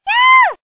One of Princess Peach's voice clips in Mario Kart: Super Circuit